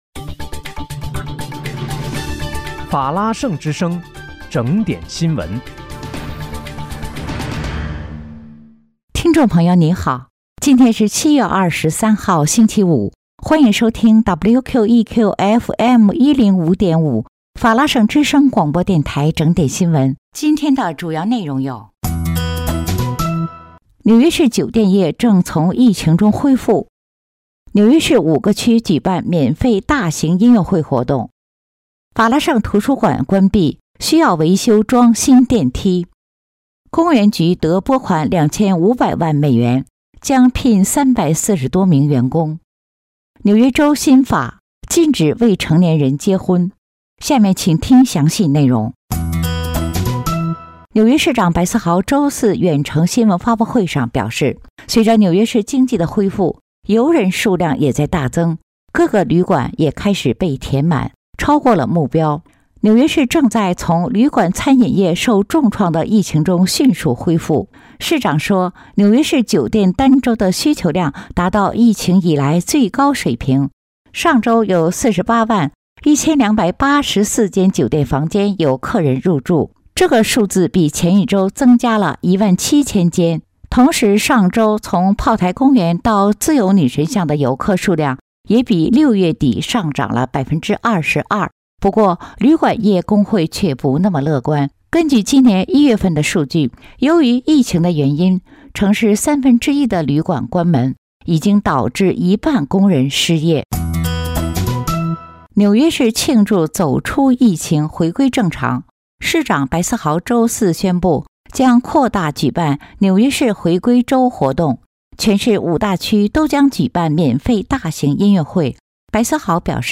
7月23日（星期五）纽约整点新闻